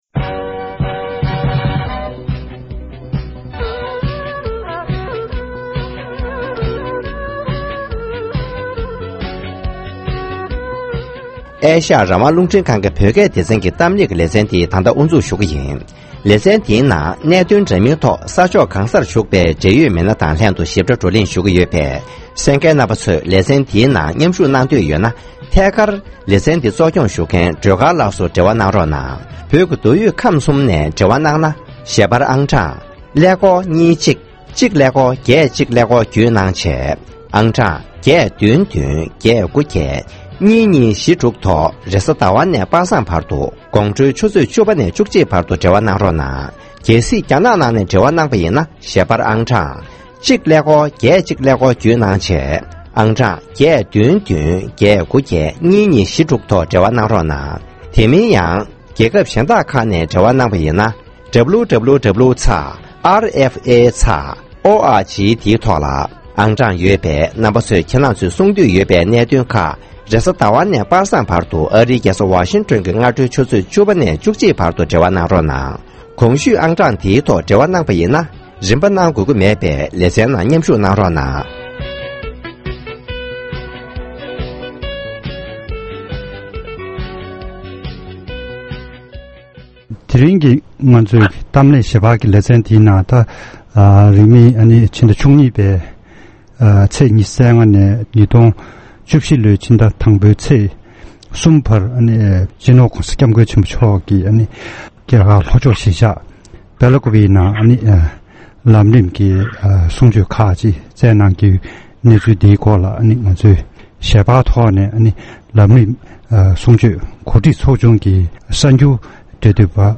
གླེང་མོལ་ཞུས་པར་གསན་རོགས༎